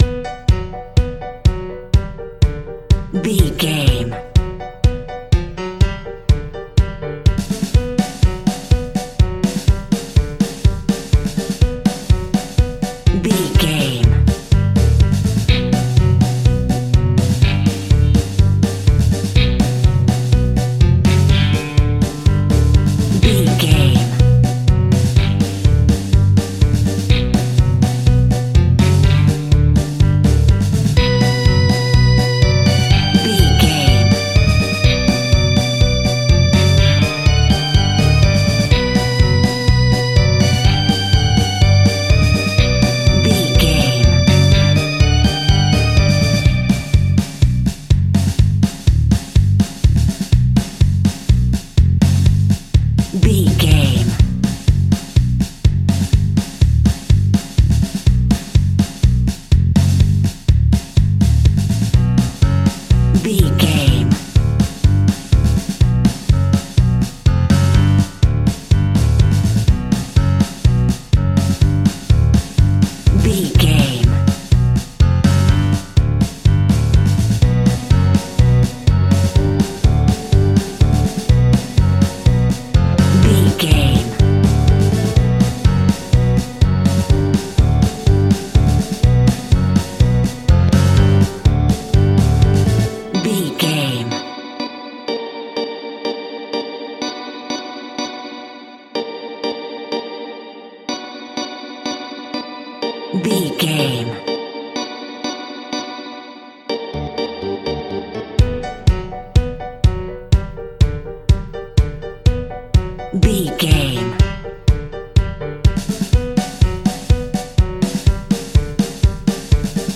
Diminished
scary
ominous
haunting
eerie
electric organ
piano
bass guitar
drums
creepy
horror music
Horror Pads
Horror Synths